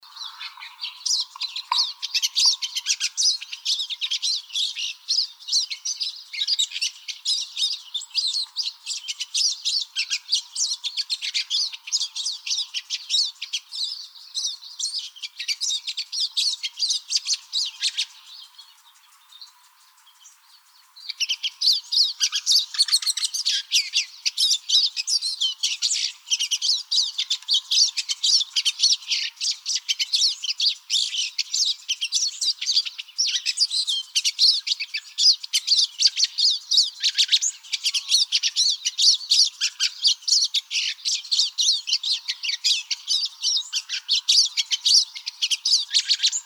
pinesiskin.wav